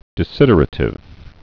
(dĭ-sĭdər-ə-tĭv, -ə-rā-, -zĭd-)